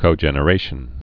(kō-jĕnə-rāshən)